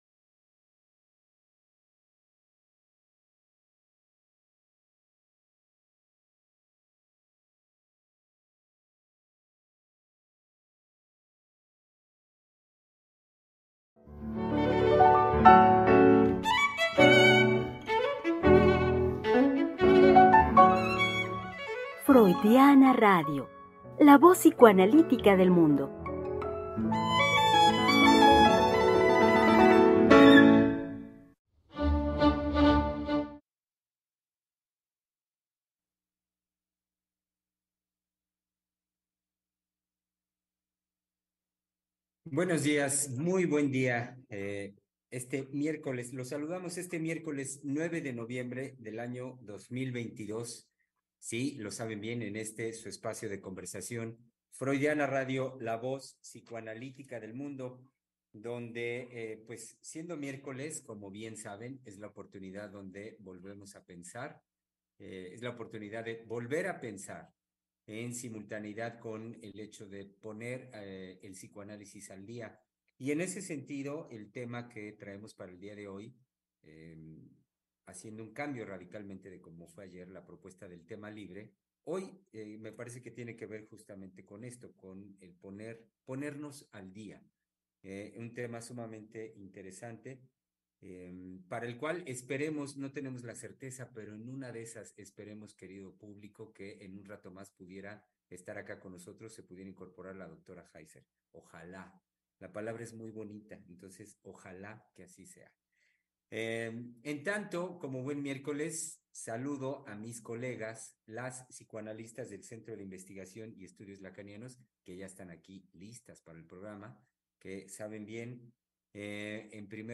Programa transmitido el 9 de noviembre del 2022.